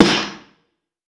TC2 Snare 8.wav